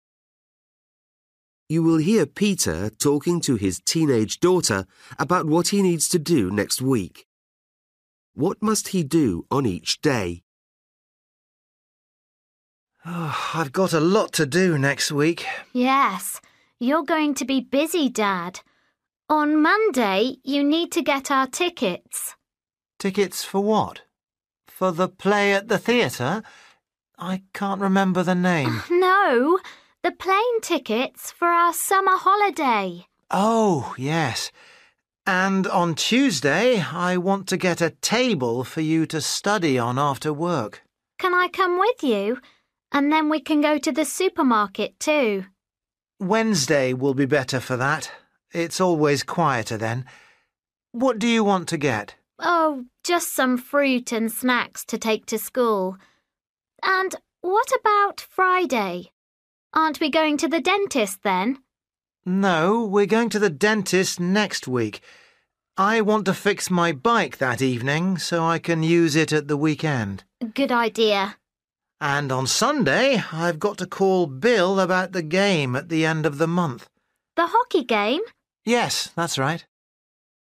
Luyện nghe trình độ A2